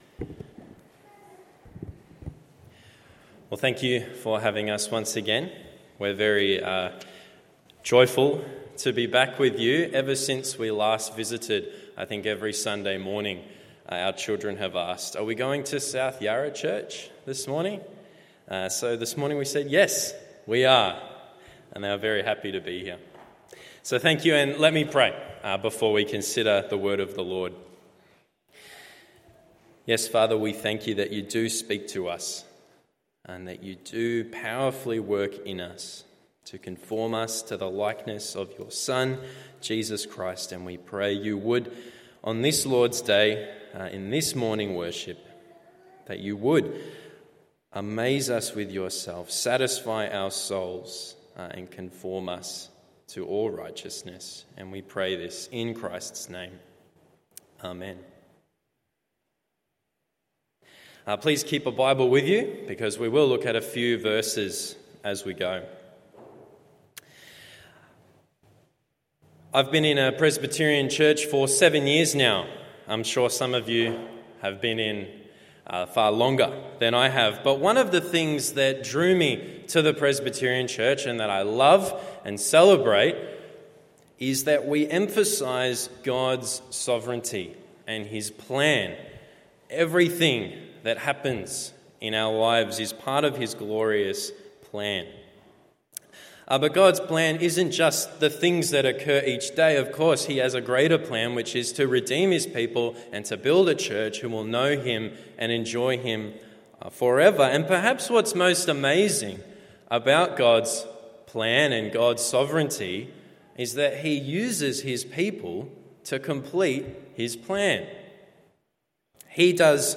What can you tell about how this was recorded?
Morning Service Colossians 1:24-29…